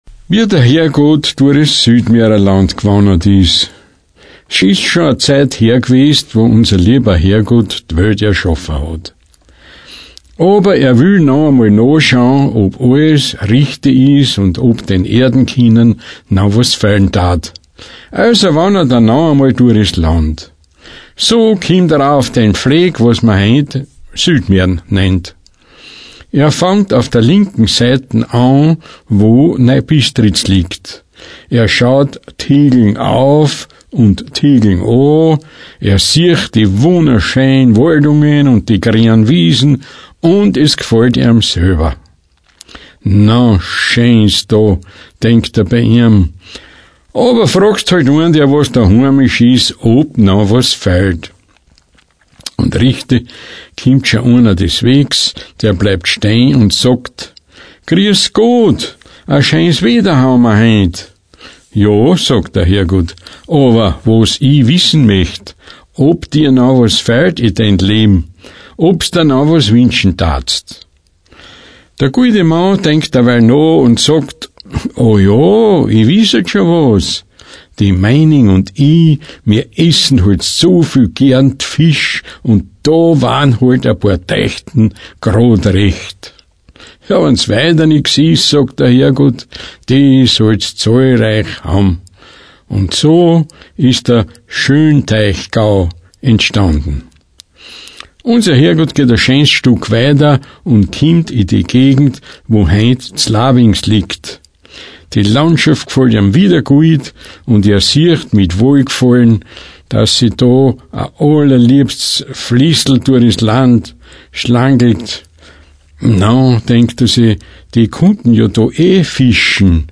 Südmähren Mundart
Mundart